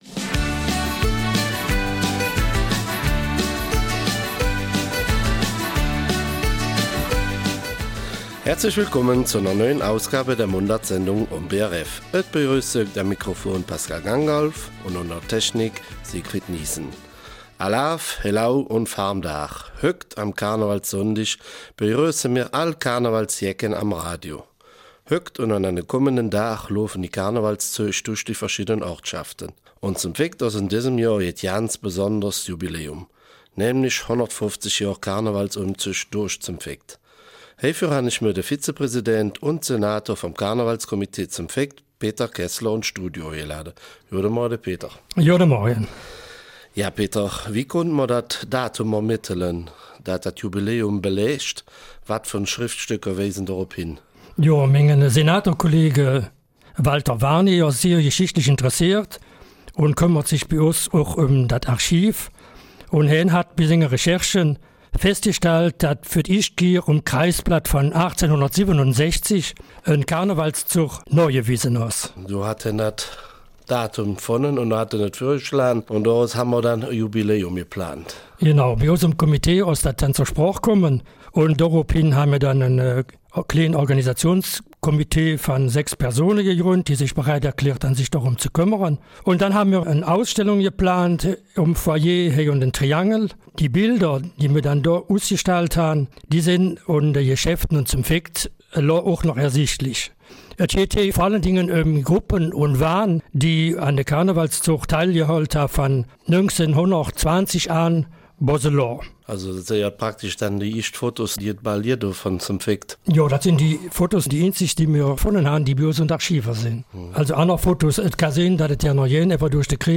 Eifeler Mundart: 150 Jahre Karnevalsumzug in St. Vith
Alaaf, Helau und Fahr’m dar - am Karnevalssonntag begrüßen wir alle Karnevalsjecken am Radio.